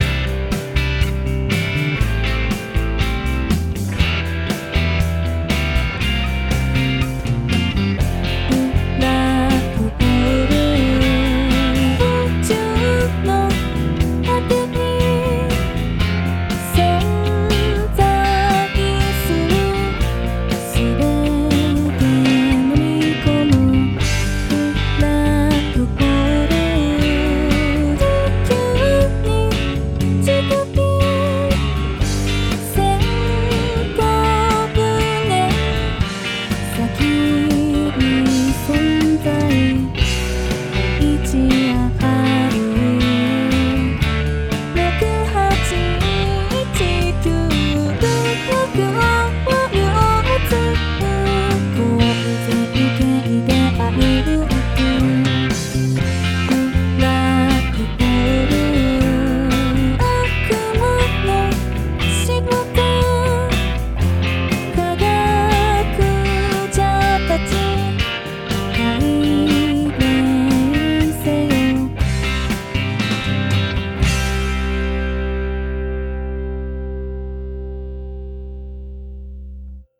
No.01200 [歌]